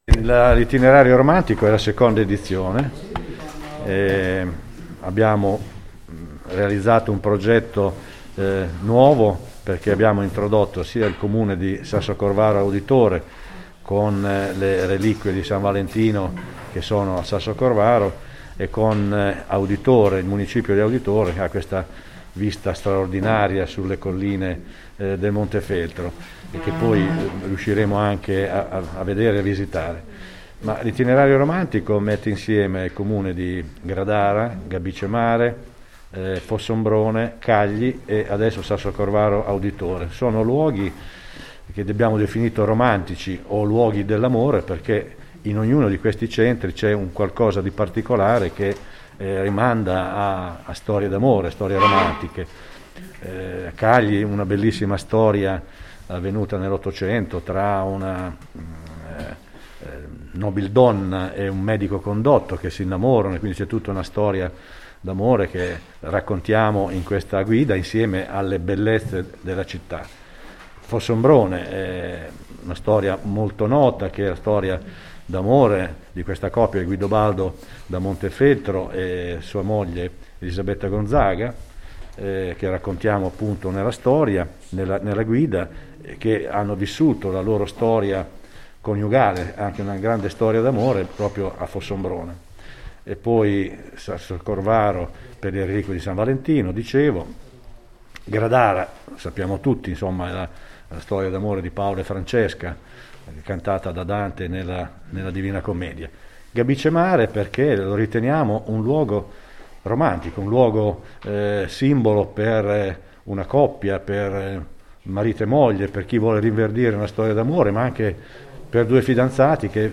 Presentato ad Auditore nella Sala Municipale la nuova edizione dell’Itinerario Romantico ideato da Confcommercio, che vede tra le novità anche l’introduzione del comune di Auditore.